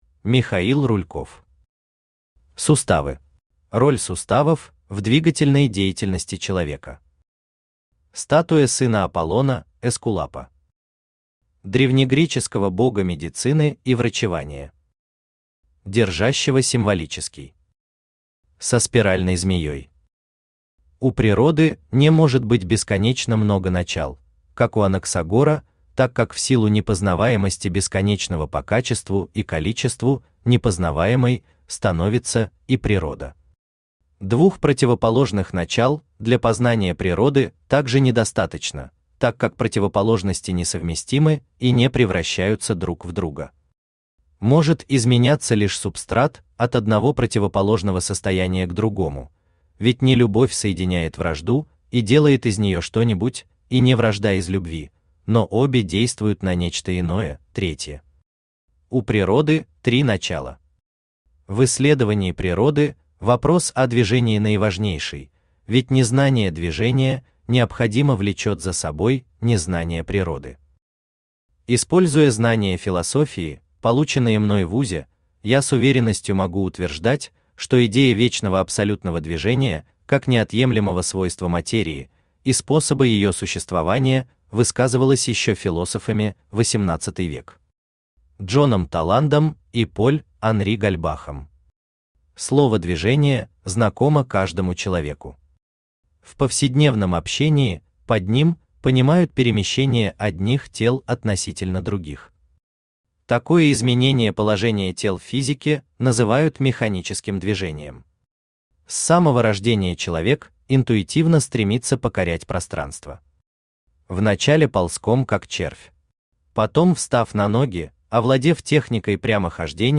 Аудиокнига Суставы | Библиотека аудиокниг
Aудиокнига Суставы Автор Михаил Михайлович Рульков Читает аудиокнигу Авточтец ЛитРес.